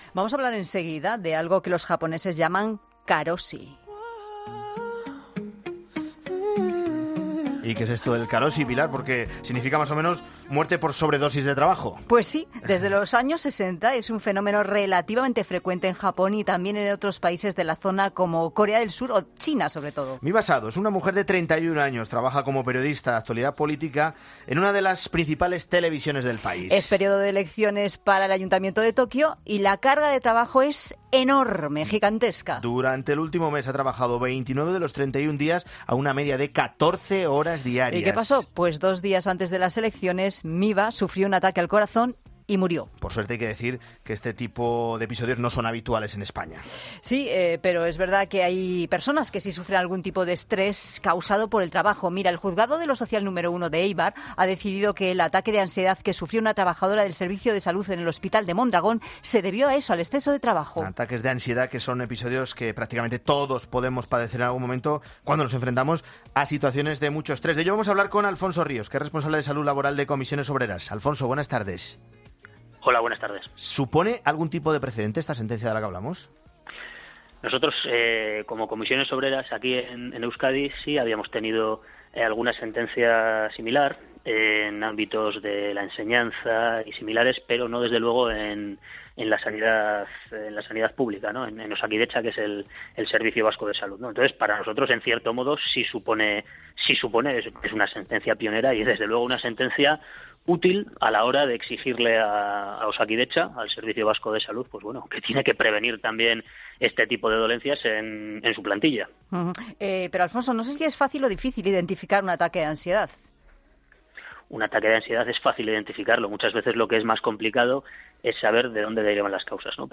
En 'Mediodía COPE'